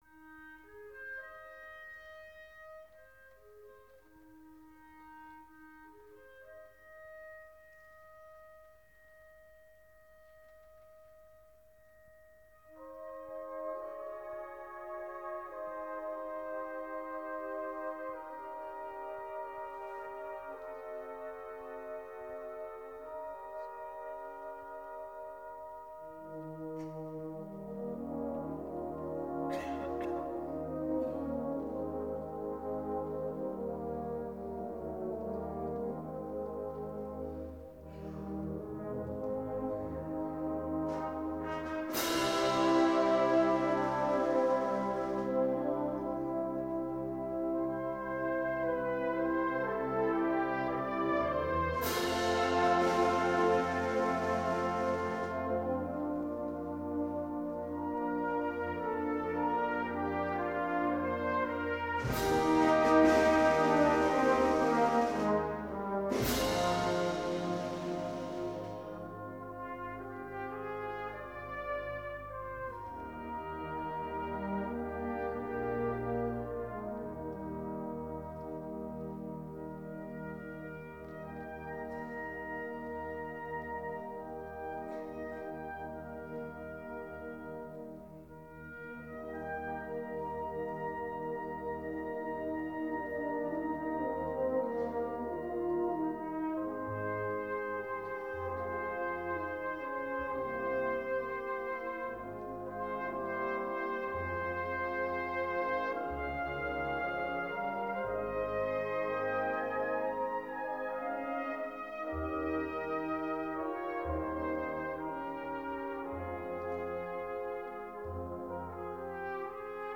Gattung: Konzertstück
Besetzung: Blasorchester